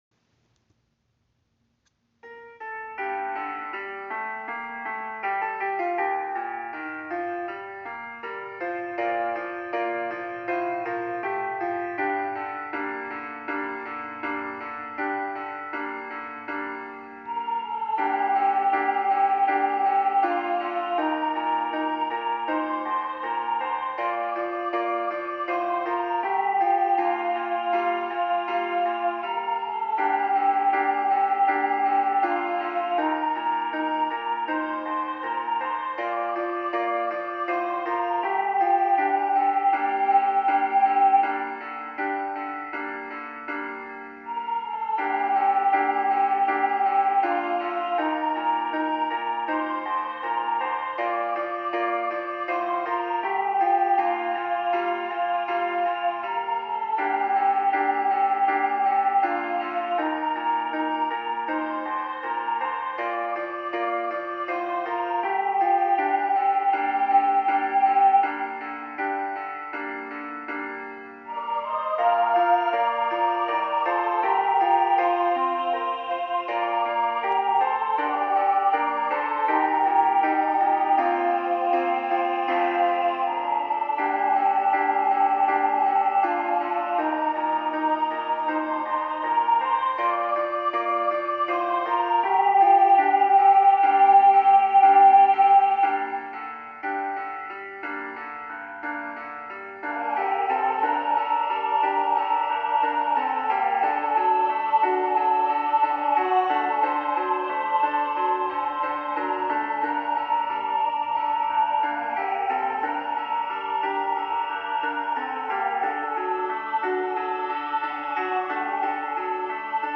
Duet
Both of these songs represent heartfelt pleas to God that we feel are not heard. In the beginning, I wanted to have two distinct voices crying out to God to represent the many voices crying to Him.
Throughout the song, both voices begin to sing the truths they know and have learned.